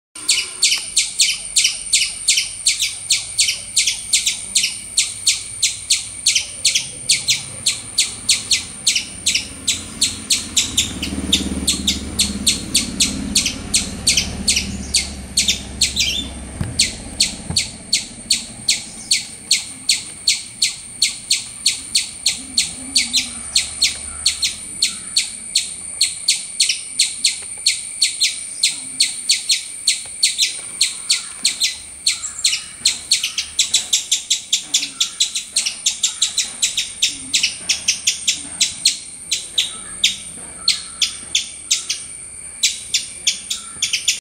جلوه های صوتی
دانلود صدای جیک جیک سنجاب از ساعد نیوز با لینک مستقیم و کیفیت بالا